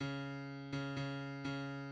{\clef bass \tempo 4=125 \key des \major \set Score.currentBarNumber = #21 \bar "" des4. des8 des4 des4}\midi{}